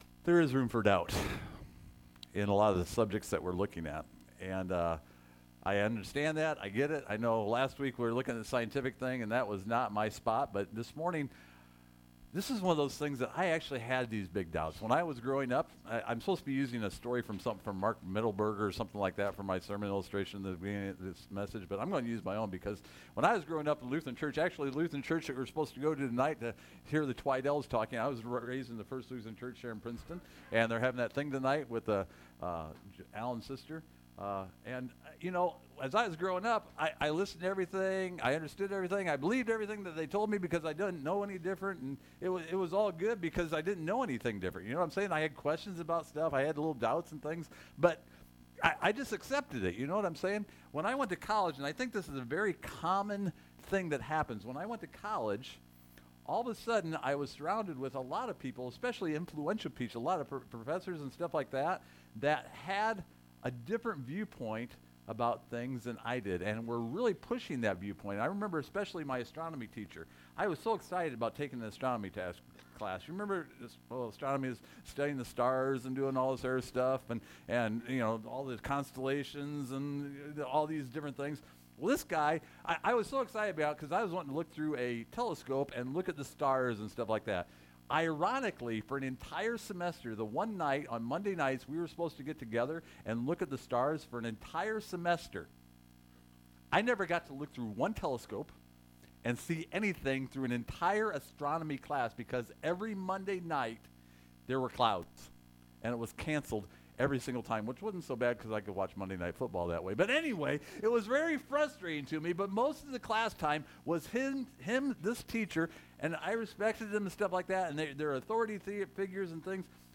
In the 3rd Sermon in the Room for Doubt series we take time to consider the Reliability of the Bible.